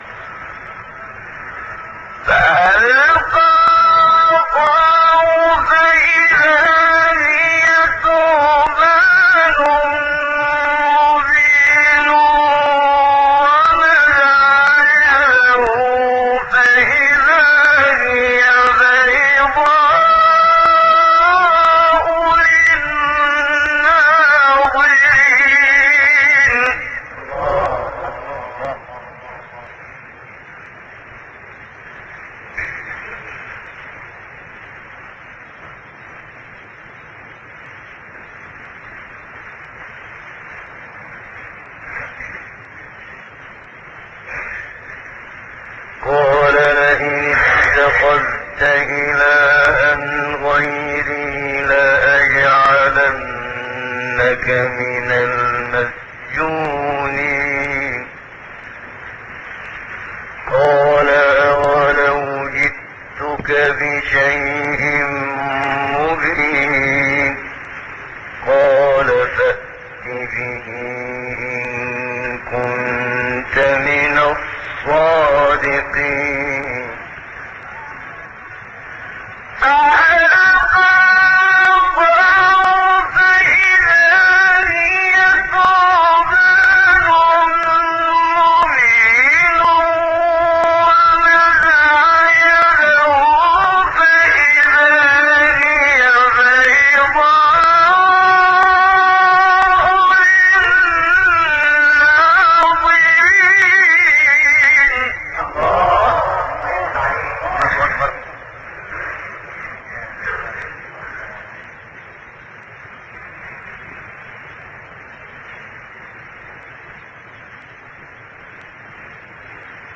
سایت قرآن کلام نورانی - منشاوی - نهاوند.mp3
سایت-قرآن-کلام-نورانی-منشاوی-نهاوند.mp3